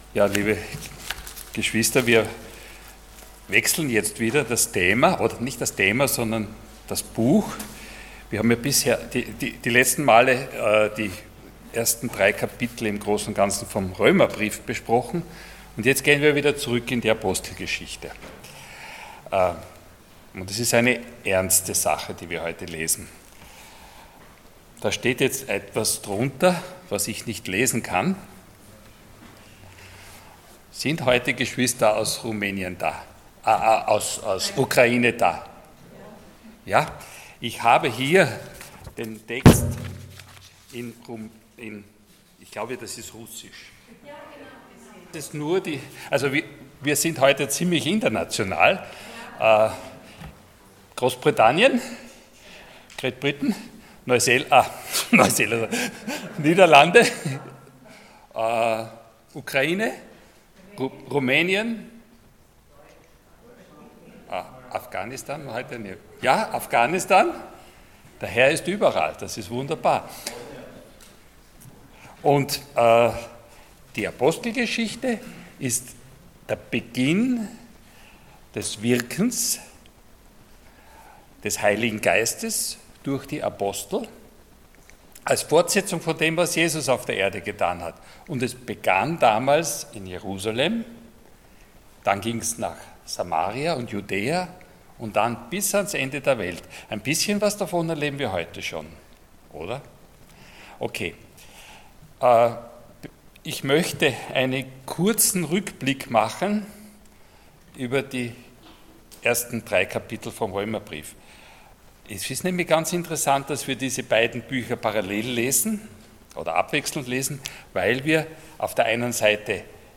Passage: Acts 5:1-16 Dienstart: Sonntag Morgen